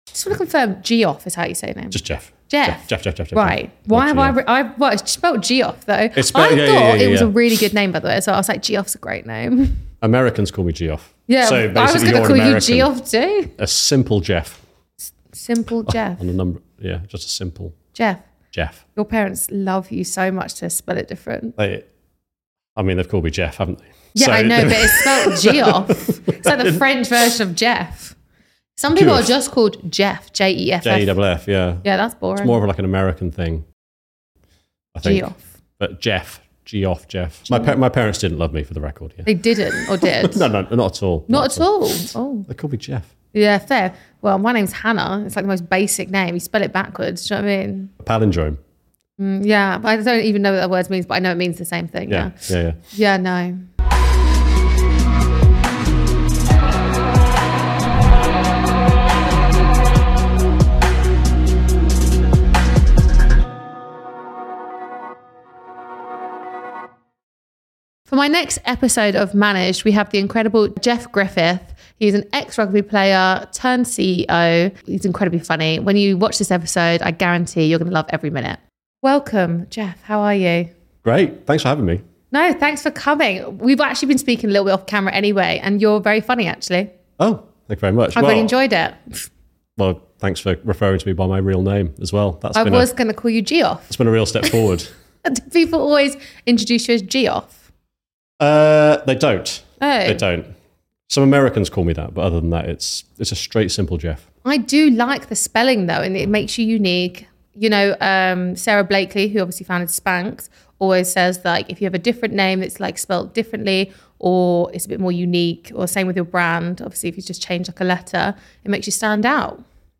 Managed is a podcast/ digital show interviewing industry leaders across media, business, sports and more who found success at a young age.